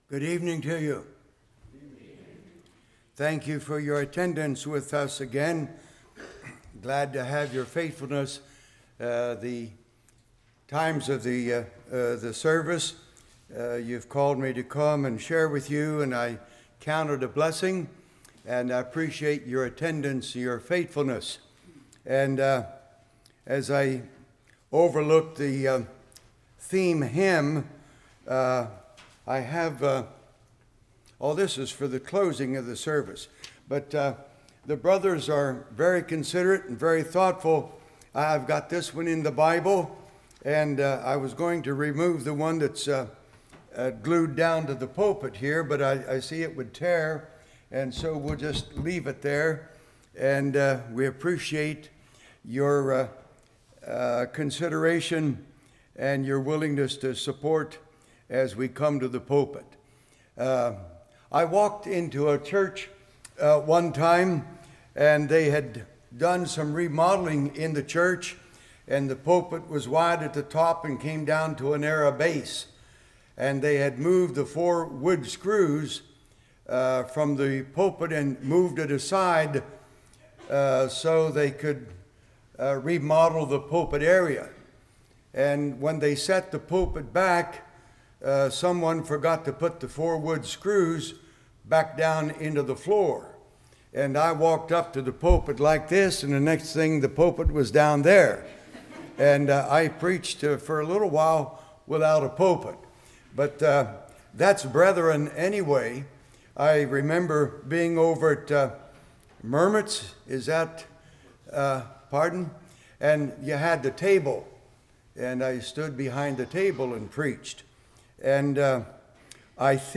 Service Type: Revival